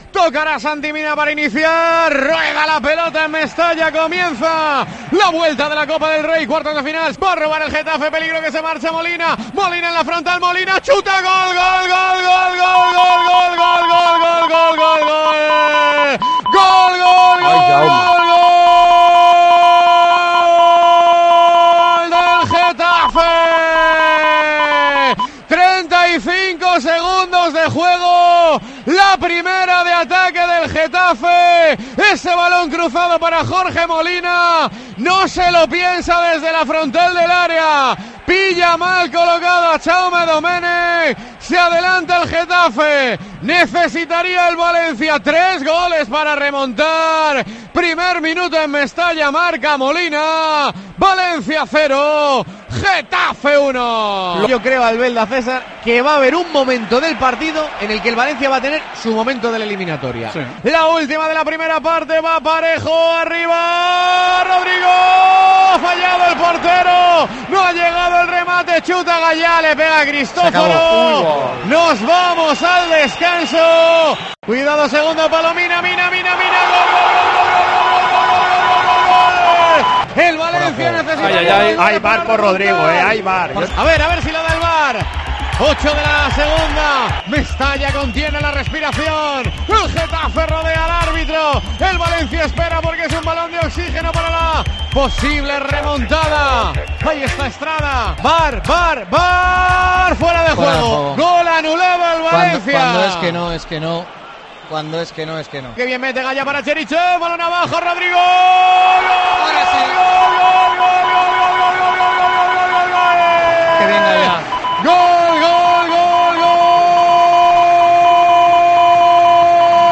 Así vivimos en Tiempo de Juego un partido inolvidable